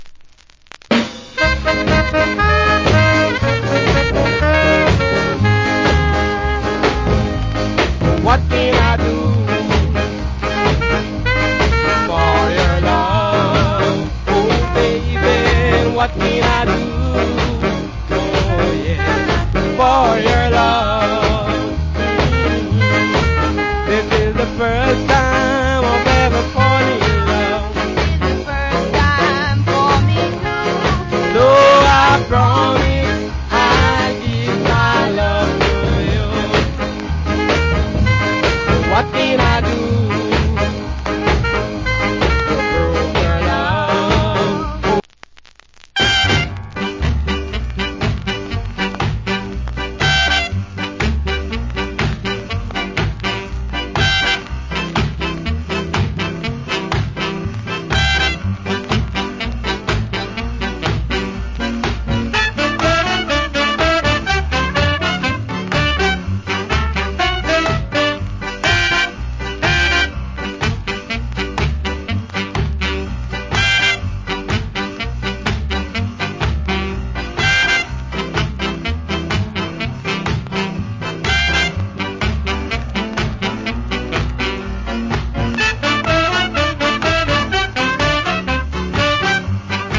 Wicked Duet Ska Vocal.